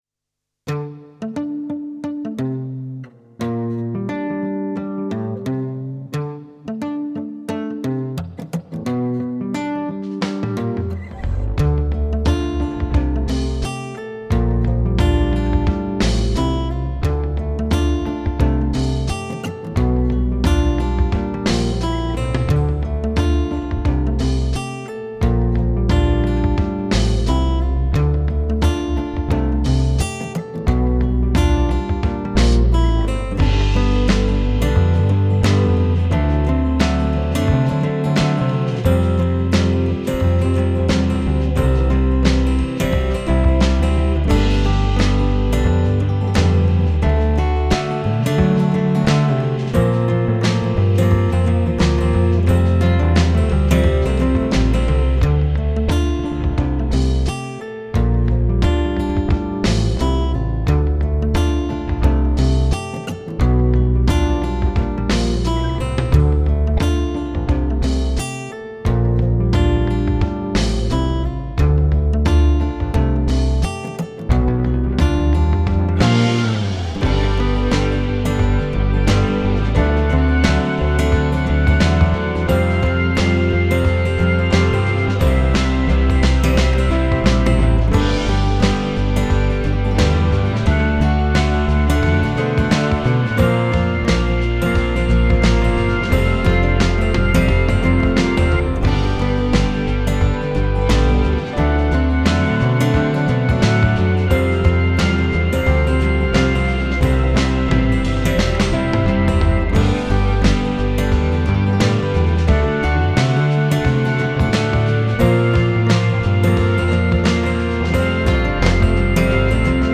Vintage steel string acoustic guitar for Kontakt